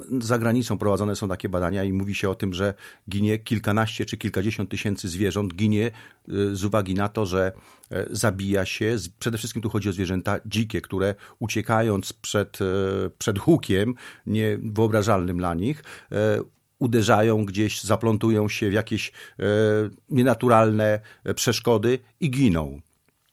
gość Radia 5